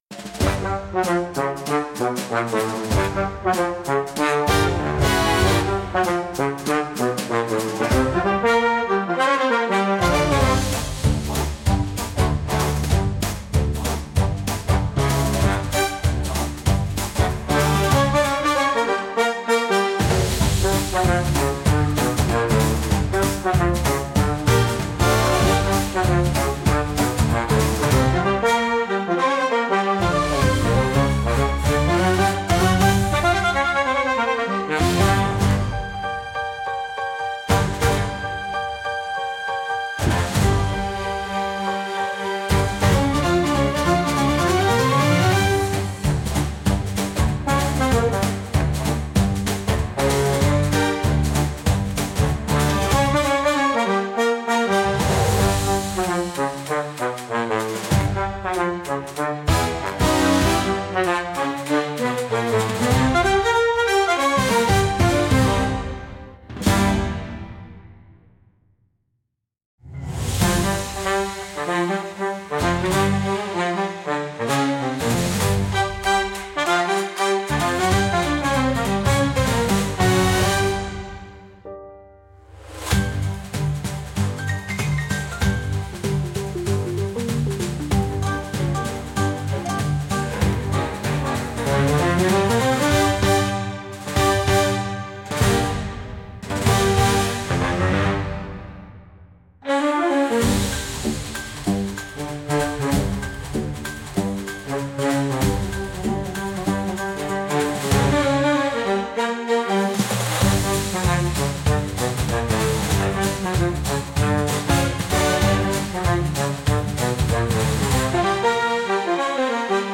Instrumental / 歌なし
チューバの「ボン・ボン」という低音が効いた、ゆったりとしたリズムの可愛いビッグバンド・ジャズ。
激しいジャズではなく、思わず横揺れしたくなるような、温かくて楽しい雰囲気が特徴です。